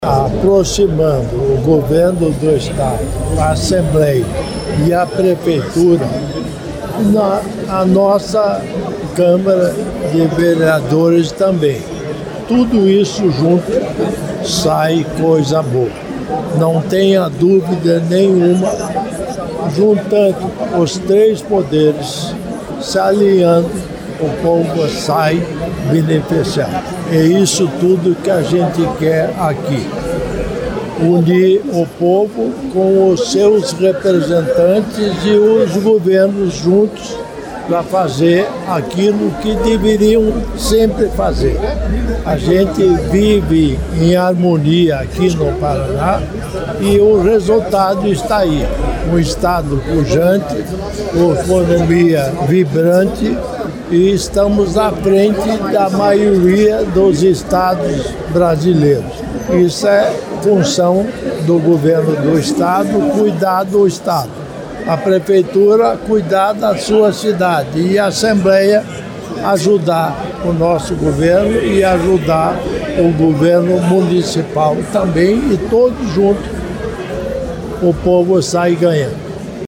Sonora do governador em exercício Darci Piana sobre o projeto Assembleia nos Bairros